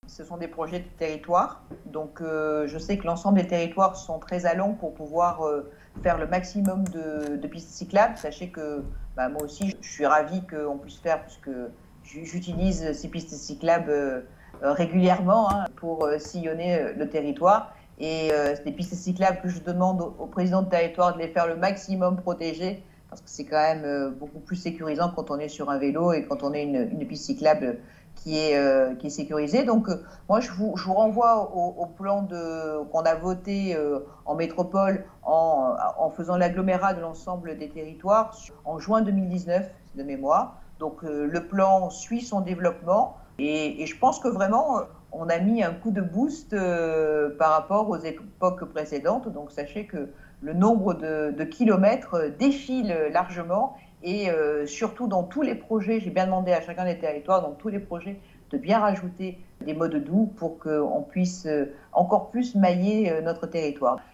Vœux à la presse de Martine Vassal : -Nous avons réalisé des promesses des années 70-